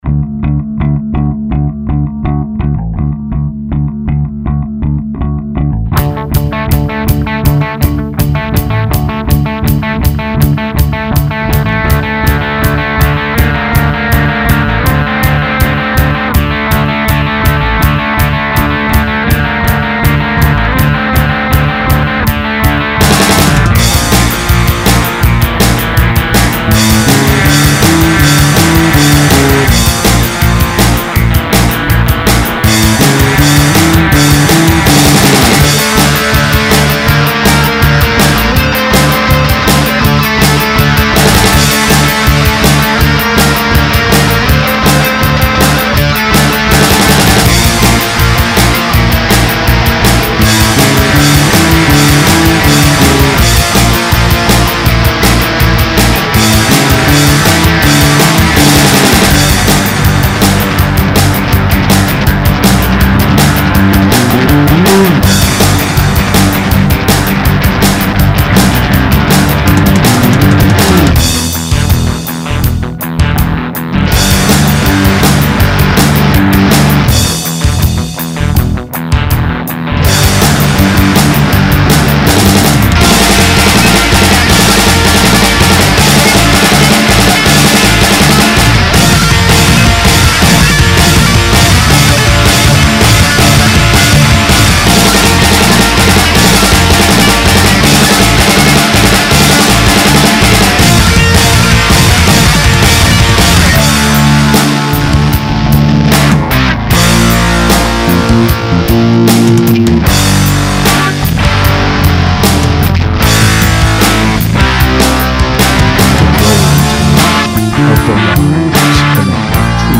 Conservative rock, Boise